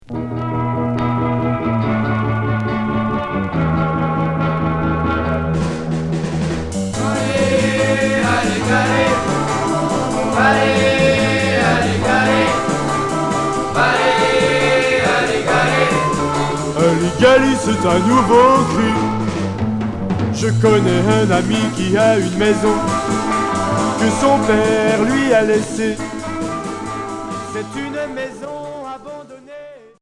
Twist